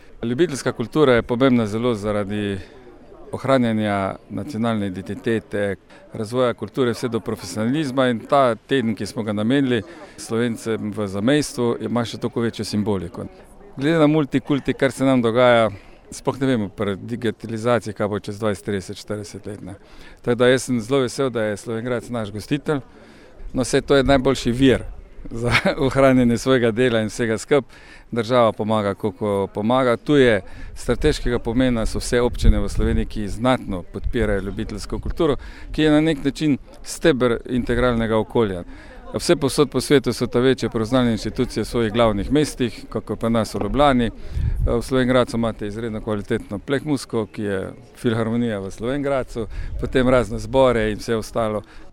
Slavnostni govorec je bil direktor organizatorjev, javnega sklada RS za kulturne dejavnosti Damjan Damjanovič: